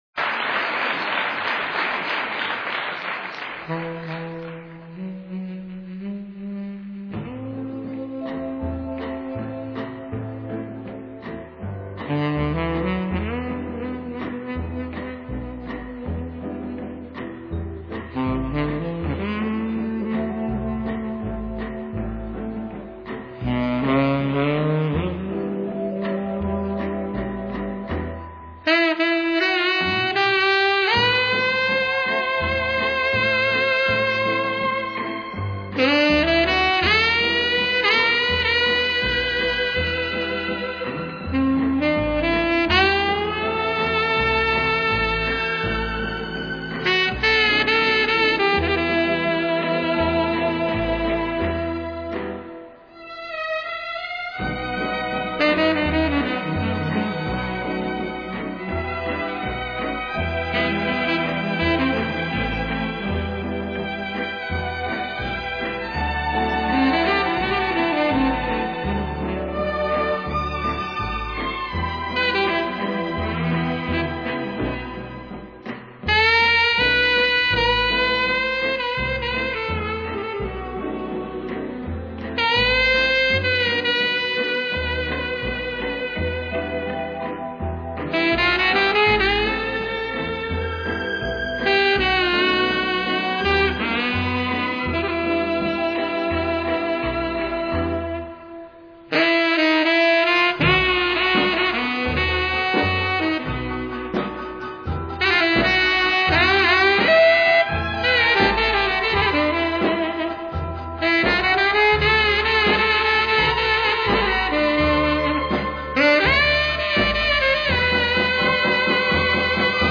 爵士音乐
Saxophone